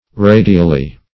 radially - definition of radially - synonyms, pronunciation, spelling from Free Dictionary
(r[=a]"d[i^]*al*l[y^])